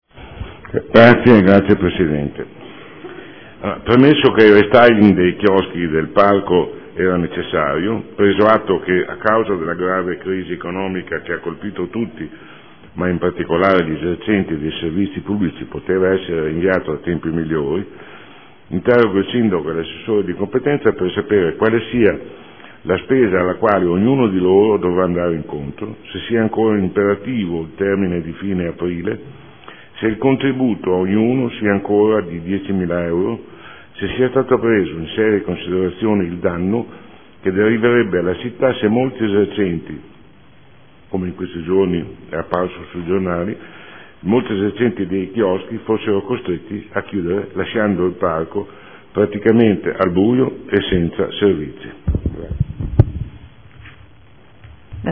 Sandro Bellei — Sito Audio Consiglio Comunale